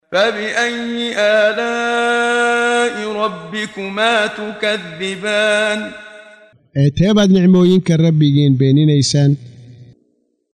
Waa Akhrin Codeed Af Soomaali ah ee Macaanida Suuradda Ar-Raxmaan ( Naxariistaha ) oo u kala Qaybsan Aayado ahaan ayna la Socoto Akhrinta Qaariga Sheekh Muxammad Siddiiq Al-Manshaawi.